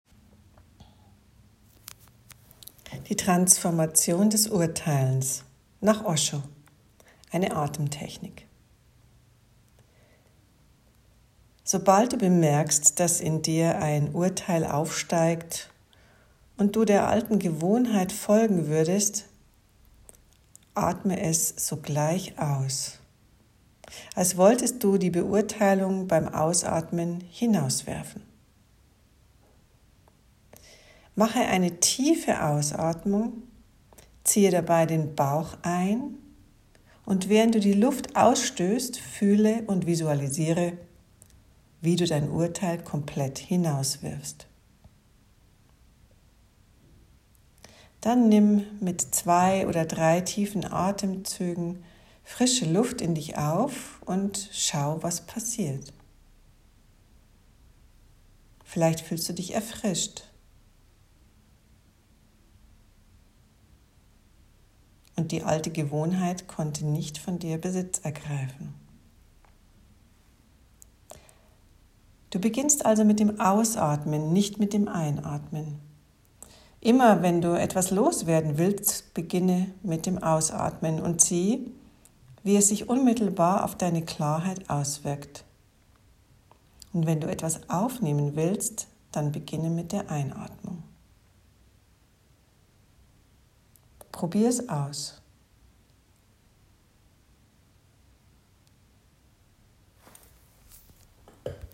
Transformation des Urteilens: Eine Atemtechnik von Osho. Knapp 2 Minuten.